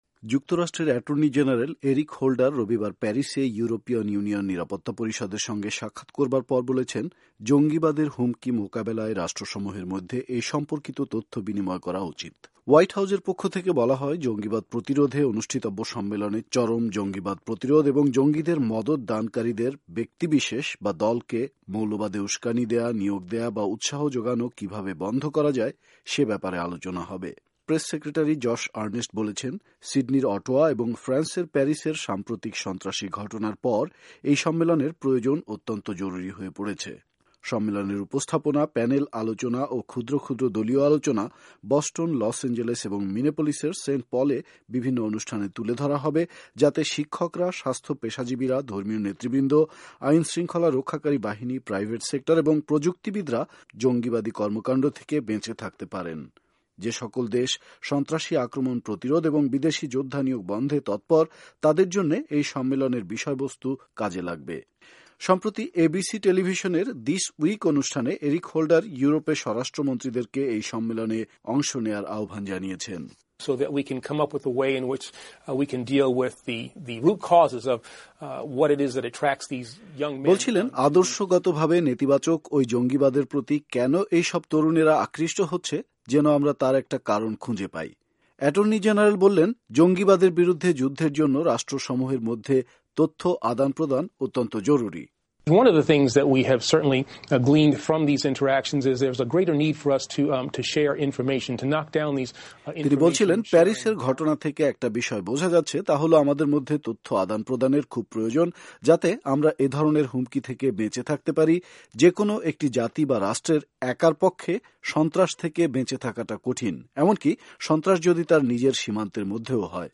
রিপোর্ট শোনাচ্ছেন